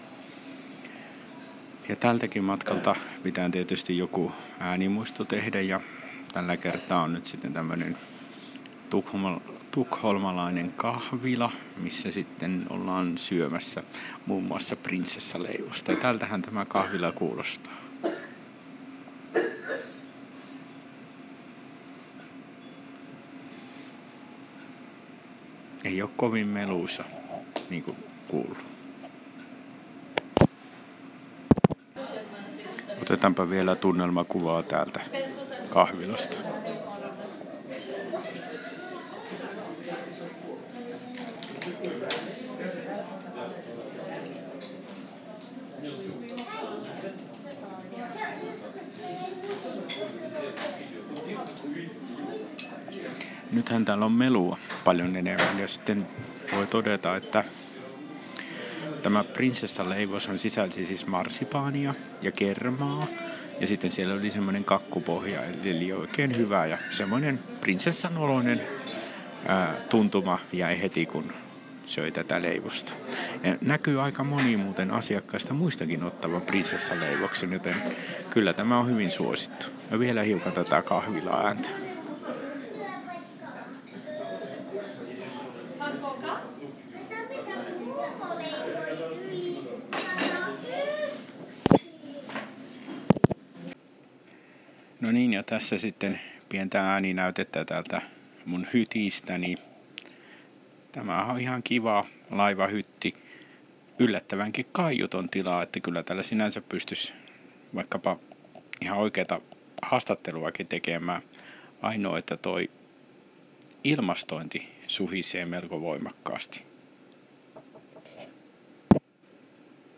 Ääniä risteilyltä